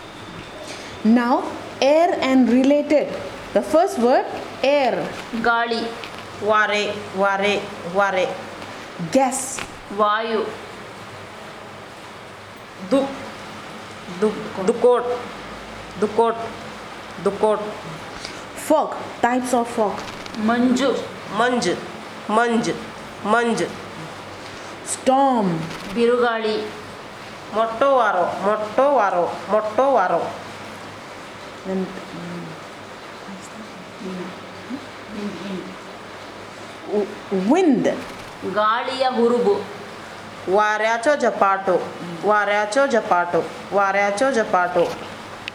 Elicitation of words about air and related